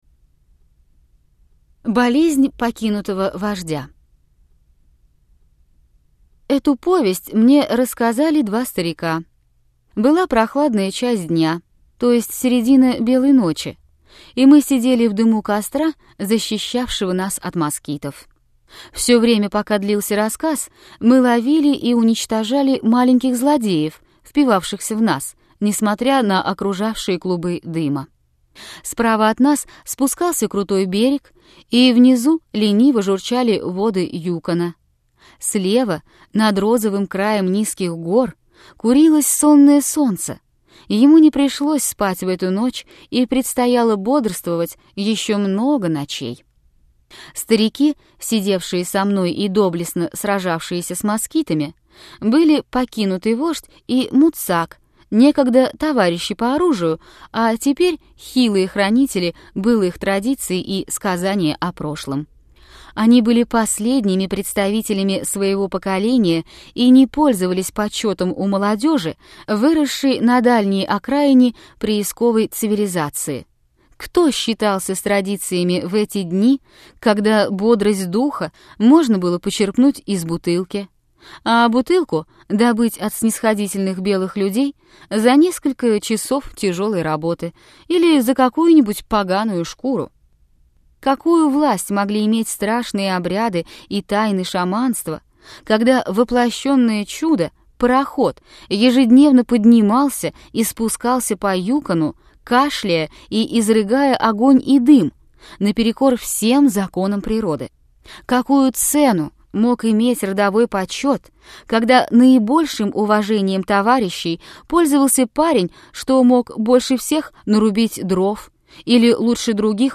Аудиокнига Дети мороза. Бог его отцов | Библиотека аудиокниг